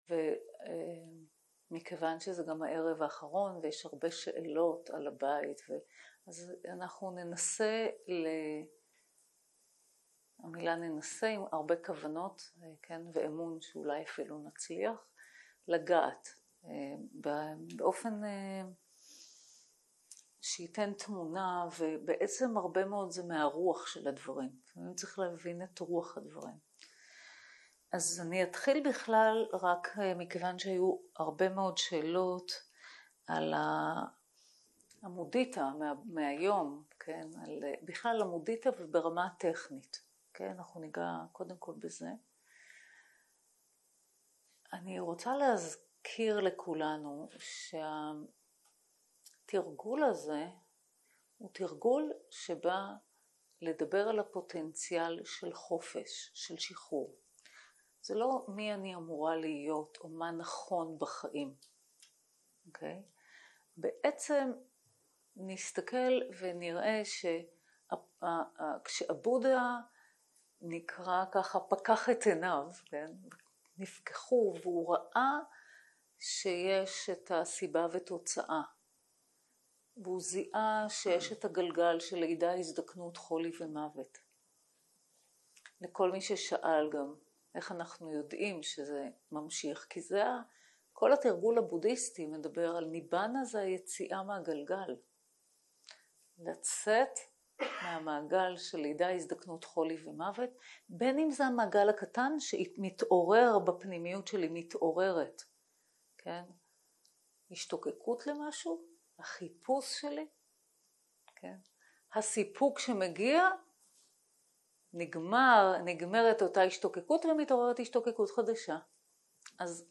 Dharma type: Questions and Answers שפת ההקלטה